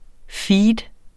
Udtale [ ˈfiːd ]